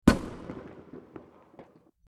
Fireworks #2 | TLIU Studios
Category: Explosions Mood: Festive Editor's Choice